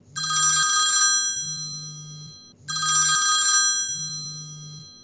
Telephone Ringing